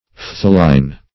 Phthalein \Phthal"e*in\, n. [See Phthalic.] (Chem.)